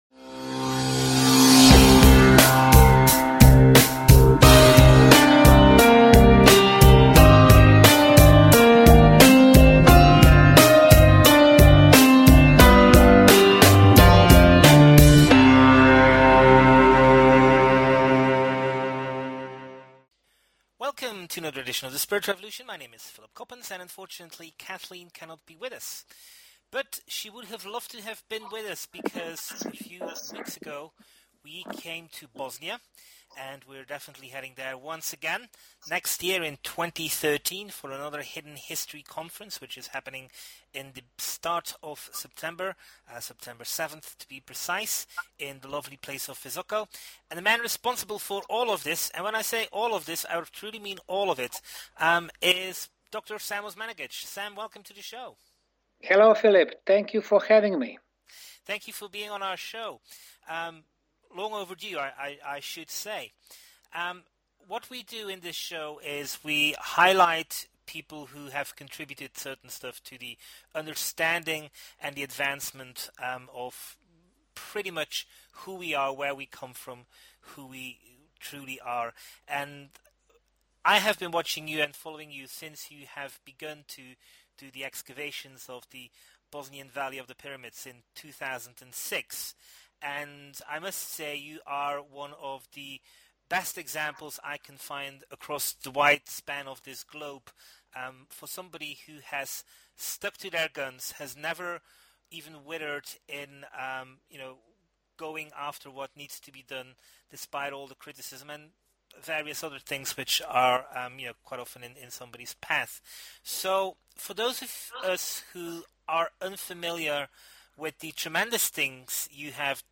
Talk Show Episode, Audio Podcast, Common_Sense_Preparedness and Courtesy of BBS Radio on , show guests , about , categorized as